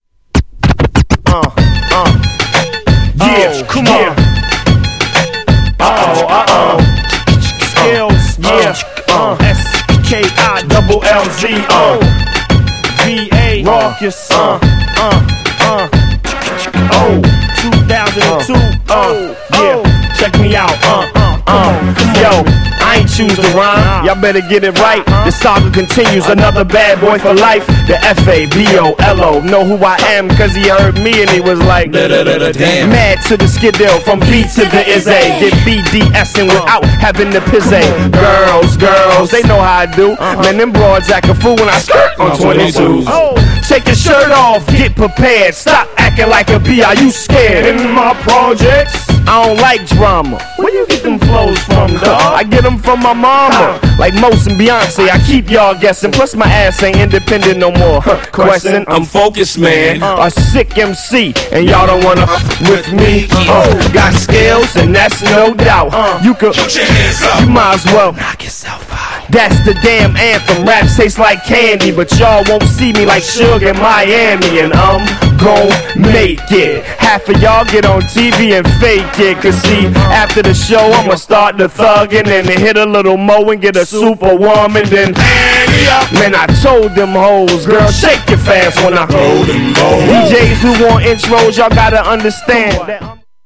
激アツFREESTYLE HIPHOP
名曲がガシガシMIXされるトラックにアツいフロウがのる
ノイズ等はありません、比較的良好です。